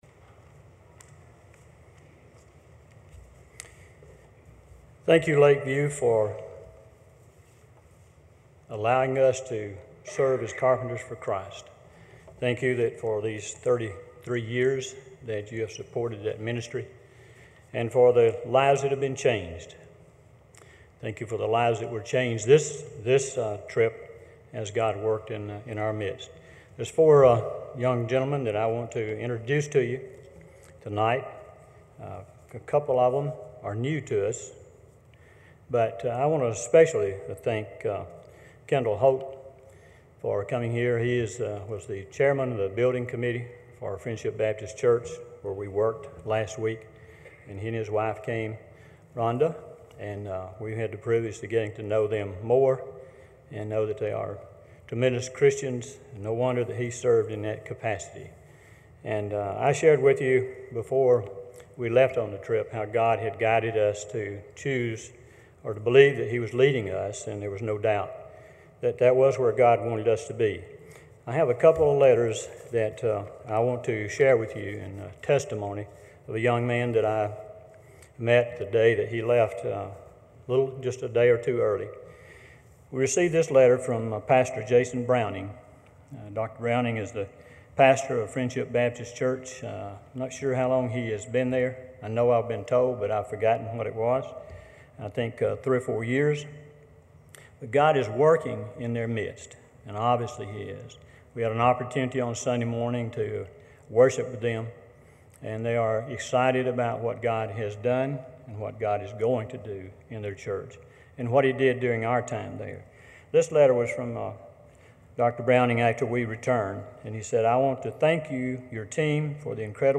Carpenter’s for Christ Testimonies